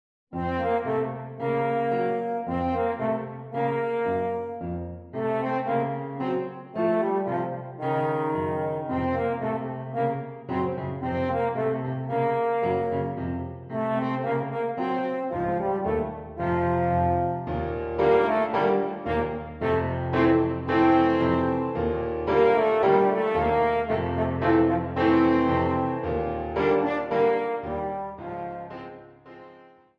A short extract from No. 6.